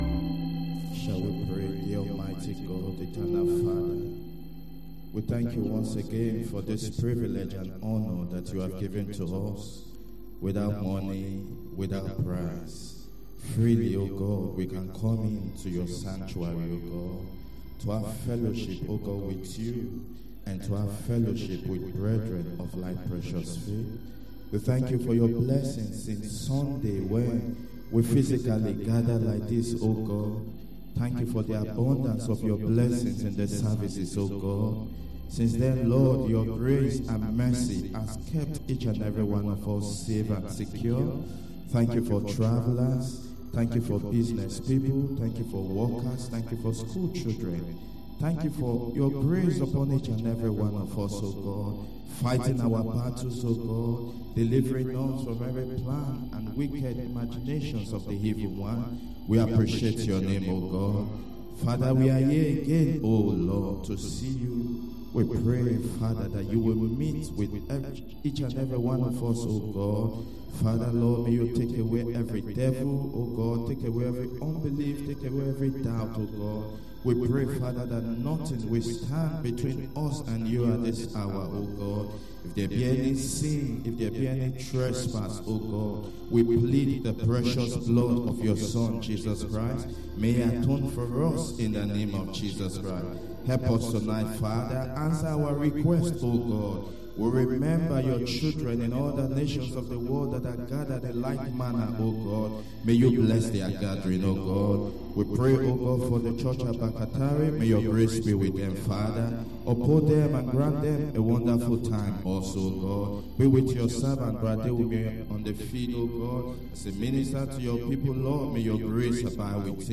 Wed. Prayer Meeting 23/10/24